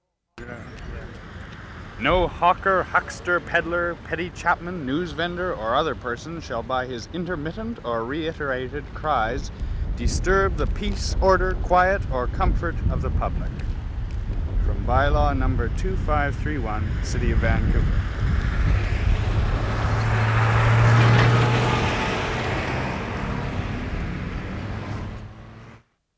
READING OF VANCOUVER NOISE BY-LAW 0'25"
Person reading Vancouver's Noise By-law on a noisy street.
Big truck driving by at end of reading.